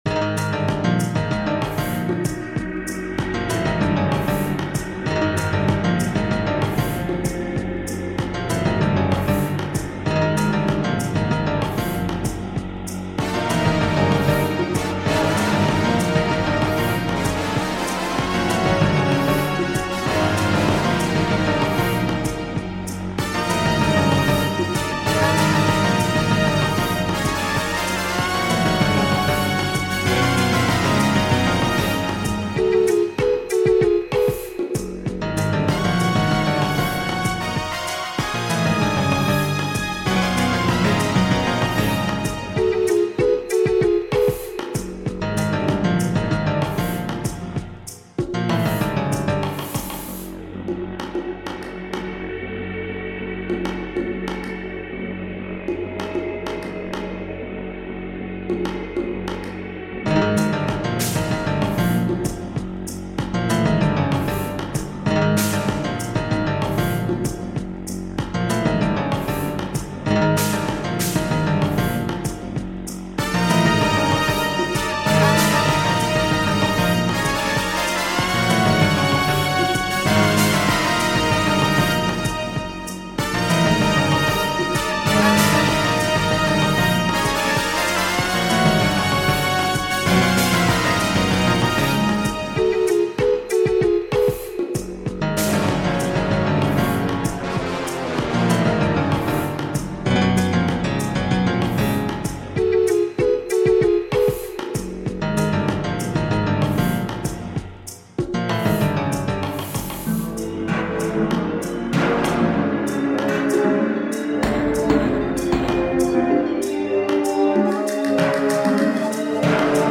Wow these were both super awesome remixes.